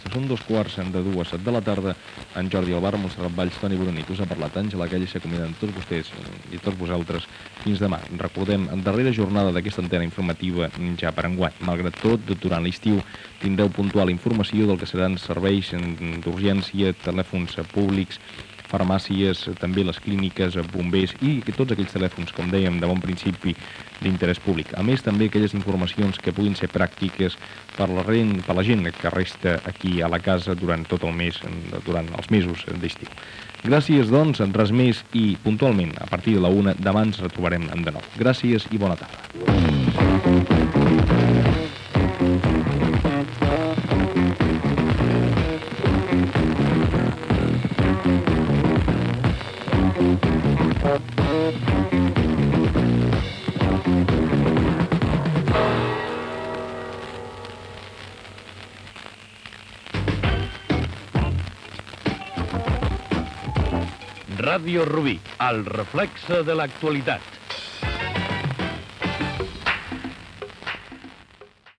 487a4e10115ef0197a3163d2db51a35277cdff4e.mp3 Títol Ràdio Rubí Emissora Ràdio Rubí Titularitat Pública municipal Nom programa Antena informativa Descripció Comiat del programa i indicatiu de l'emissora. Gènere radiofònic Informatiu